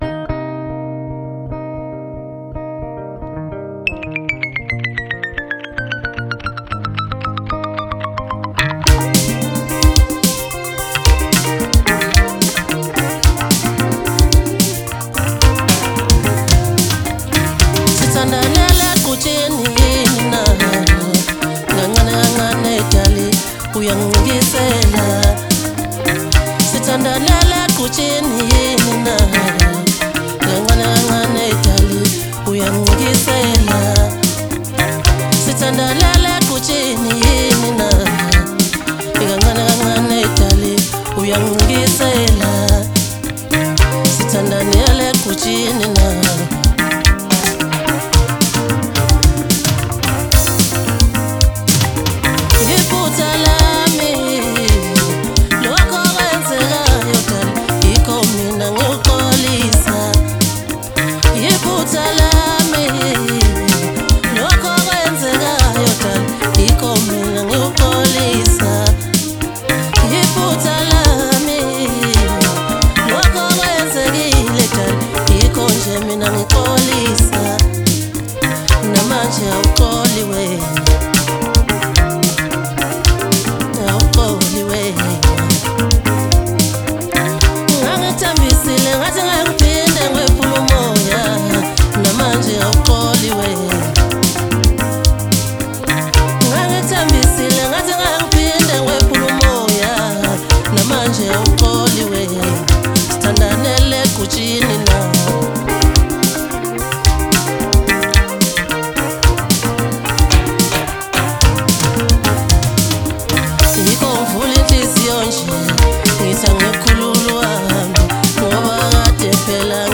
MASKANDI MUSIC
hit maskandi song